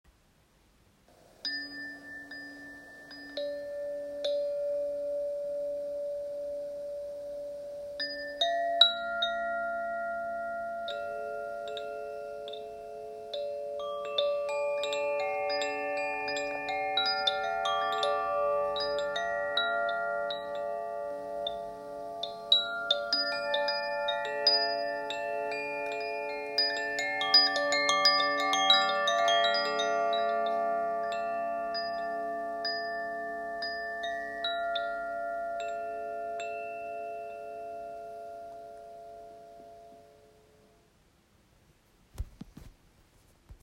Wind chime 8 integrated metal rods
Tuning: La, Ré, Fa, Sol, La, Ré, Fa, La Oiled bamboo body